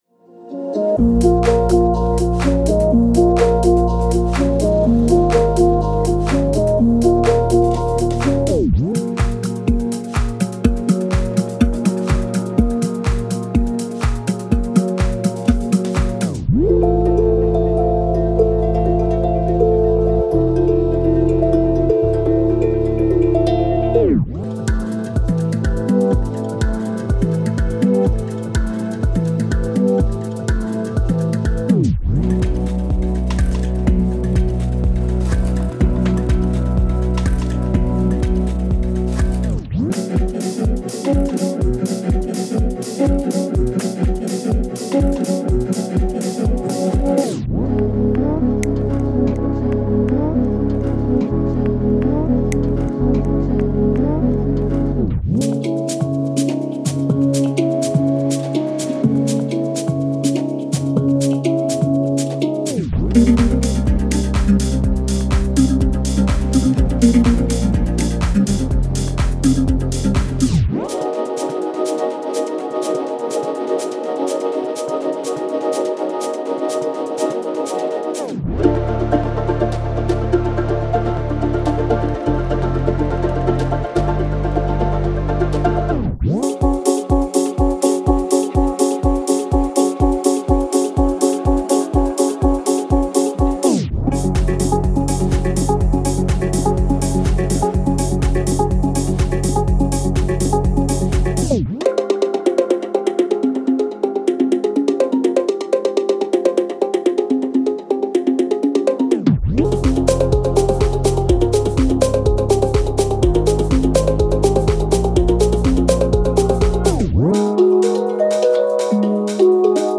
その豊かで神秘的な音色により、作品に静けさと神秘性を加えることができます。
その結果、オーガニックとシンセティックのサウンドが動的に融合し、トラックに独自でモダンなエッジを加えます。
デモサウンドはコチラ↓
Genre:Downtempo